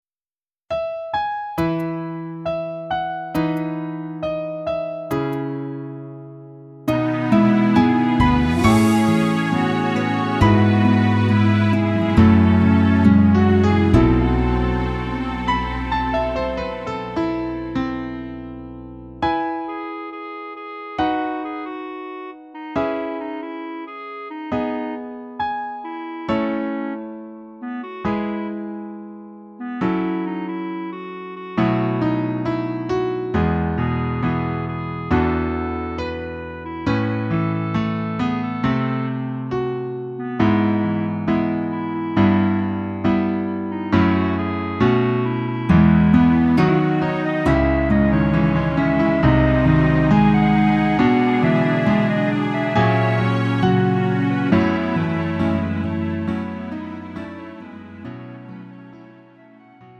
음정 원키 3:47
장르 가요 구분 Lite MR